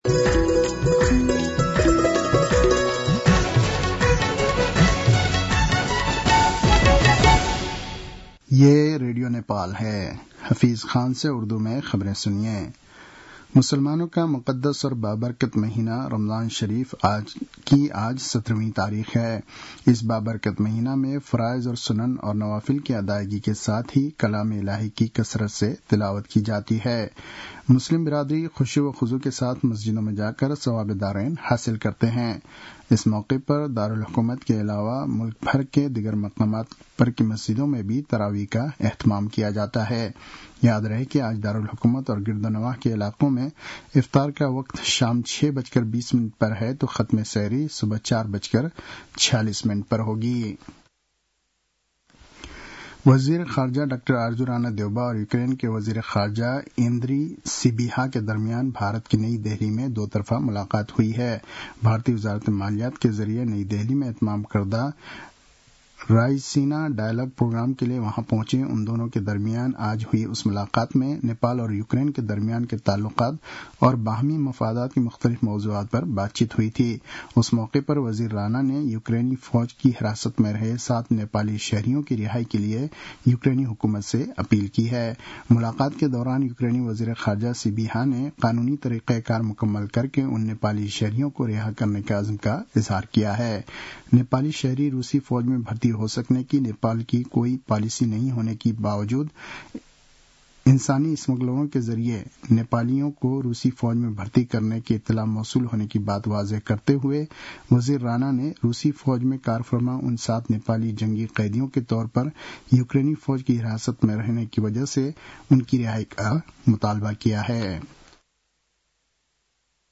उर्दु भाषामा समाचार : ५ चैत , २०८१